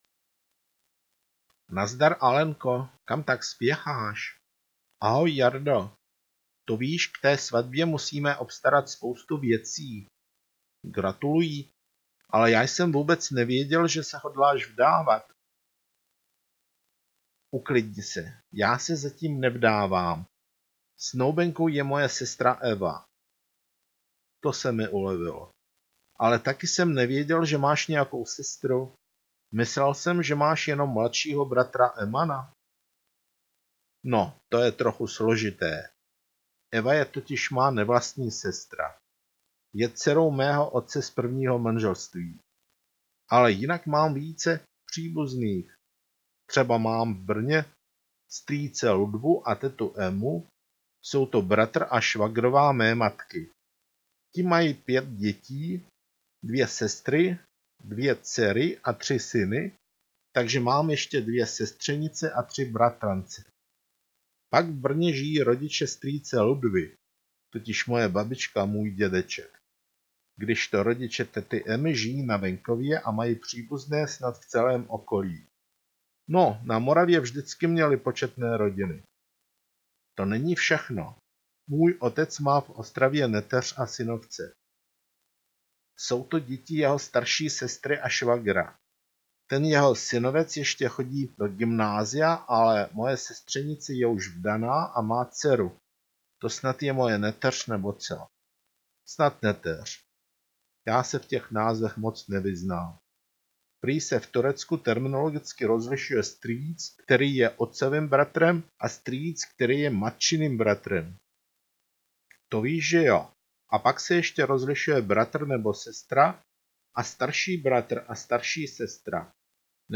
Произношение чешских гласных и согласных звуков